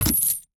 UIMvmt_Menu Medieval Chains 03.wav